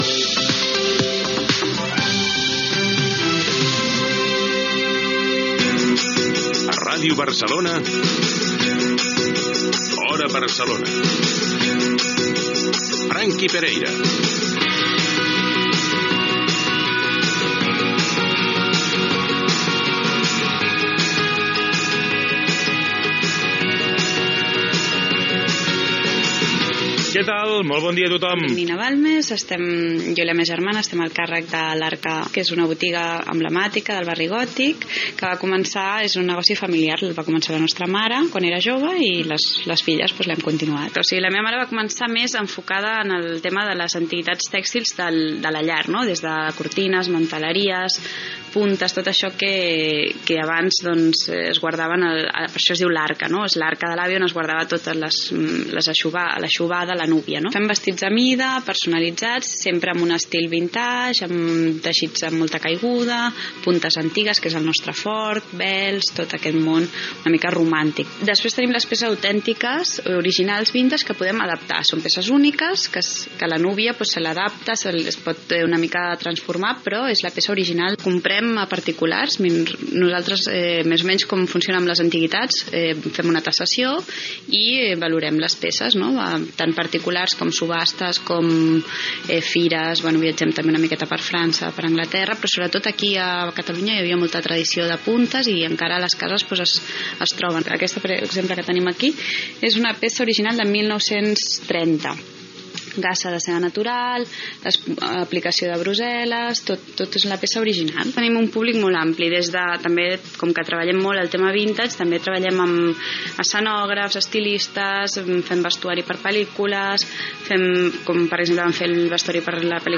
Indicatiu del programa, salutació
Informatiu